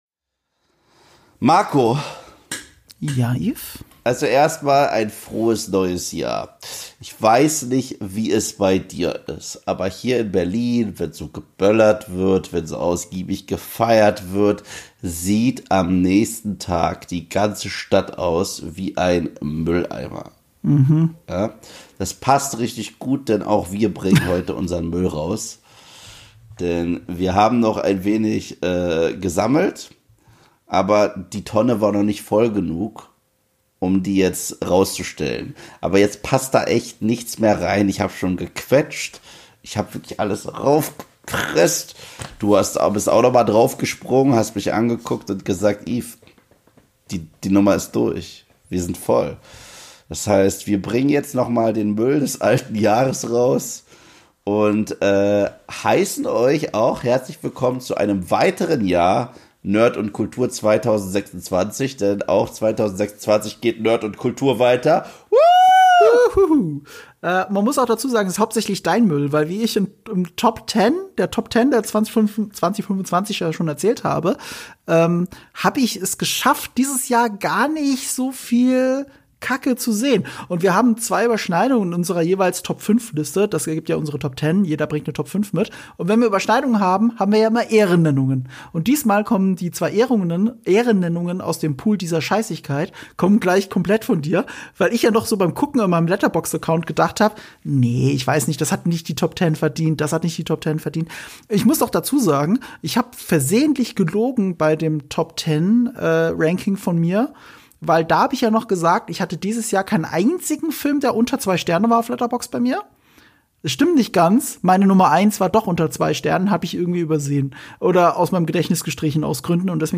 Wir haben sie gesehen, sodass ihr es nicht müsst: die wirklich schlechtesten Filme des Jahres im Ranking. Nachtrag: Entschuldigt bitte das Schmatzen.